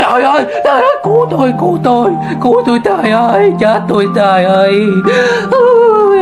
Tải âm thanh "Trời ơi cứu tui trời ơi" - Hiệu ứng âm thanh chỉnh sửa video